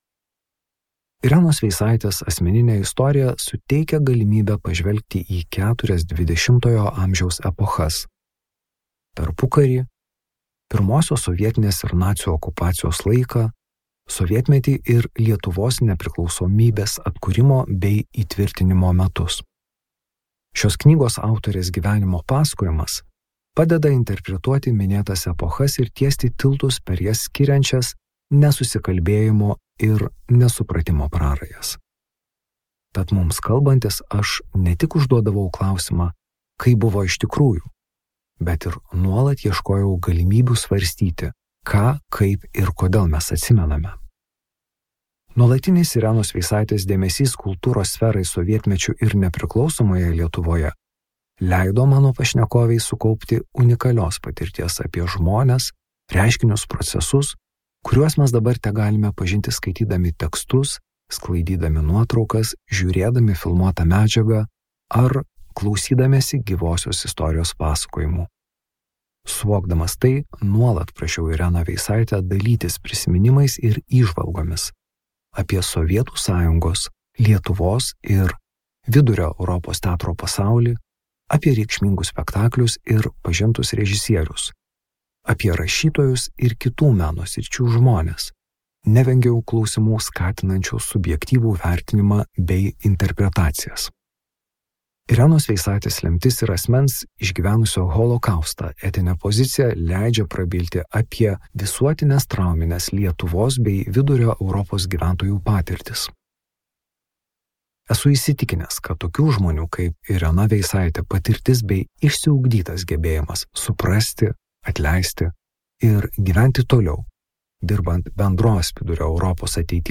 Skaityti ištrauką play 00:00 Share on Facebook Share on Twitter Share on Pinterest Audio Irena Veisaitė.